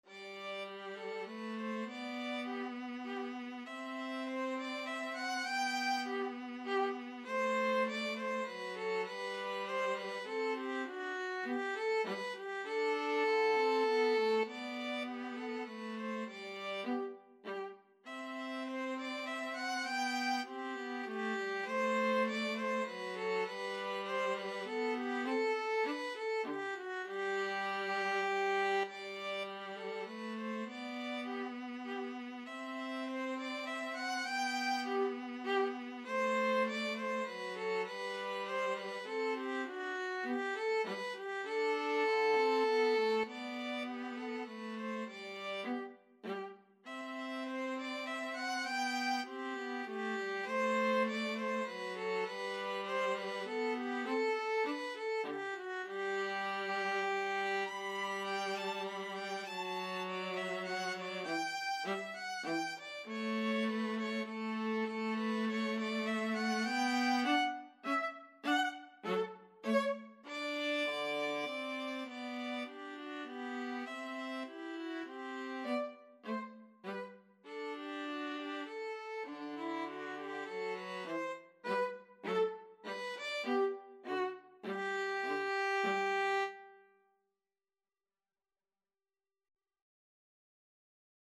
3/4 (View more 3/4 Music)
Violin-Viola Duet  (View more Easy Violin-Viola Duet Music)
Classical (View more Classical Violin-Viola Duet Music)